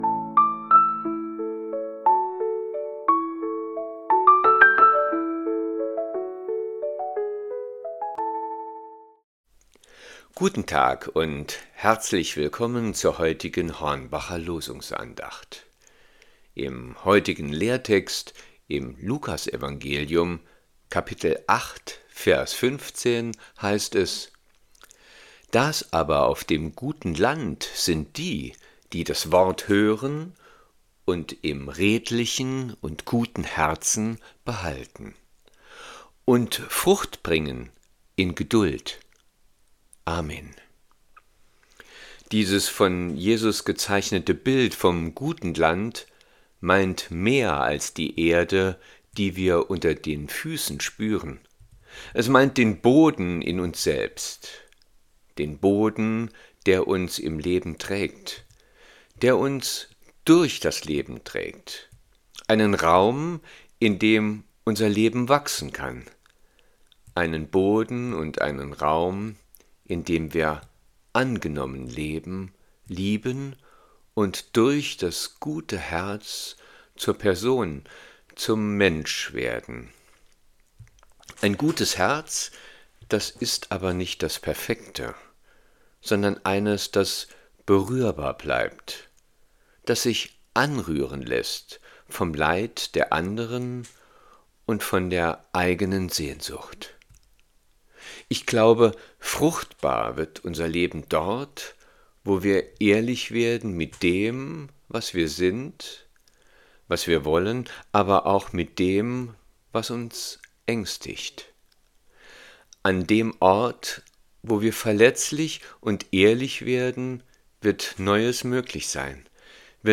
Losungsandacht für Samstag, 01.11.2025